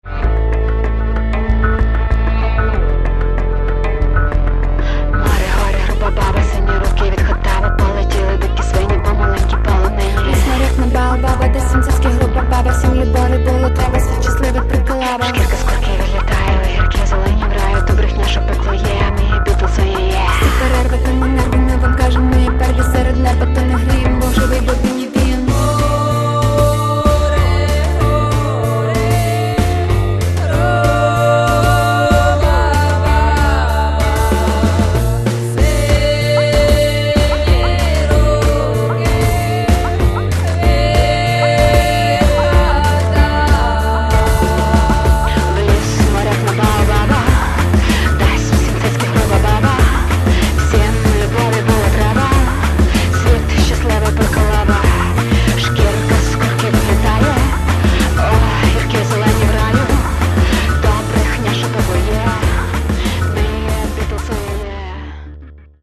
Каталог -> Рок и альтернатива -> Электронная альтернатива